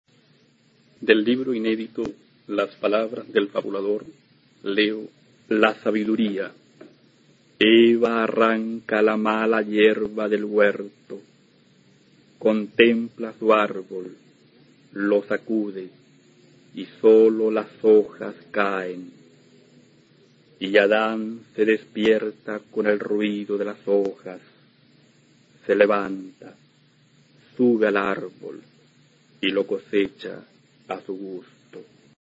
A continuación puedes escuchar a Jaime Quezada, autor chileno de la Generación del 60, recitando su poema La sabiduría, del libro "Las palabras del fabulador" (1968).
Poema